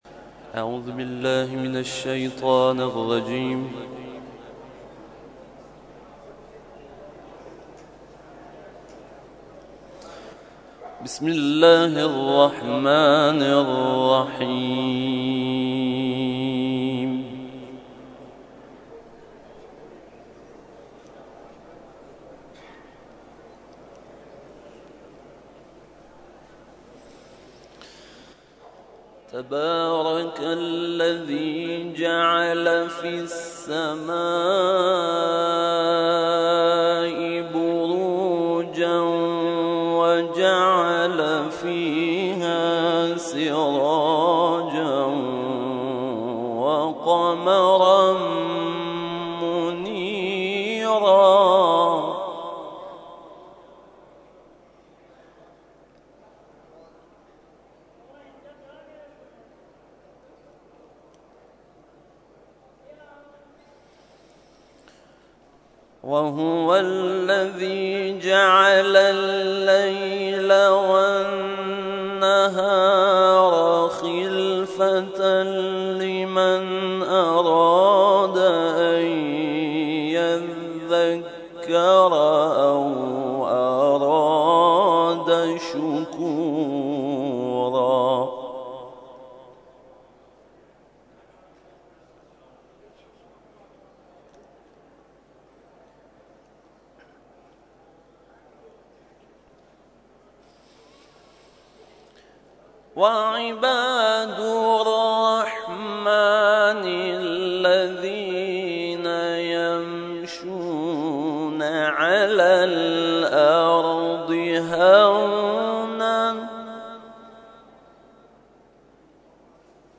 نماز جمعه
محافل و مراسم قرآنی
تلاوت قرآن کریم